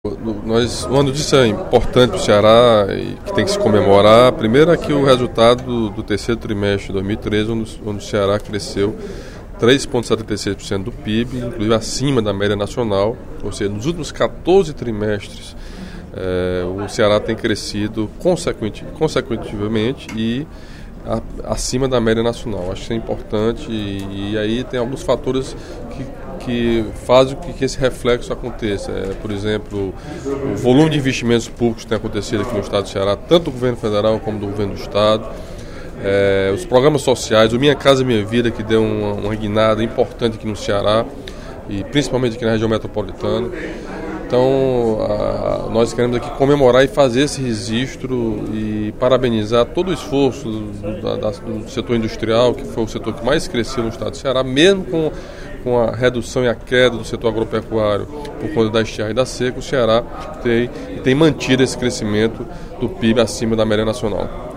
Durante o primeiro expediente da sessão plenária desta quinta-feira (12/12), o deputado Camilo Santana (PT) apresentou relatório do Instituto de Pesquisa e Estratégia Econômica do Ceará (Ipece), divulgado na última terça-feira (11), apontando que a economia do Ceará continua crescendo mais que a média nacional.